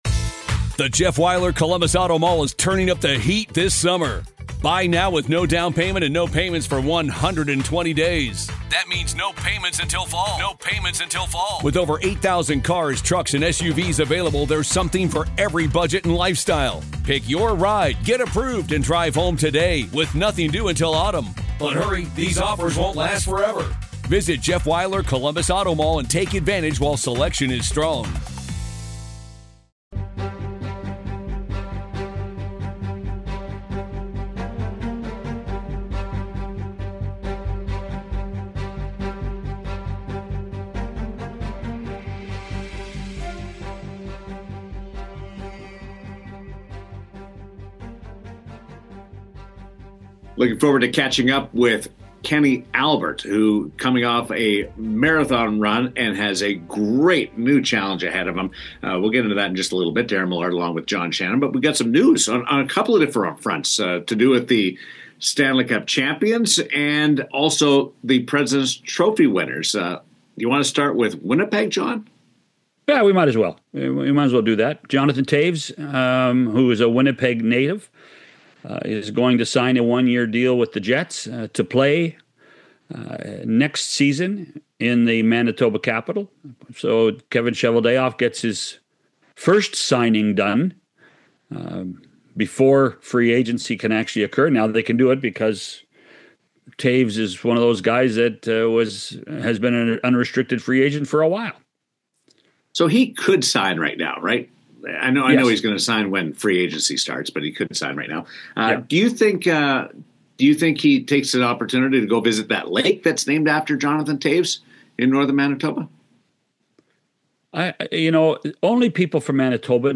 Kenny Albert—the busiest man in broadcasting—joins the show to reflect on calling the Stanley Cup Final and share what’s next on his jam-packed schedule.